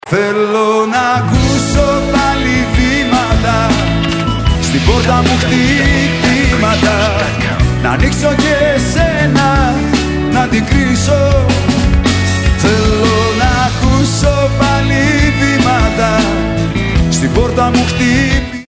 CD 2 (Live in New York):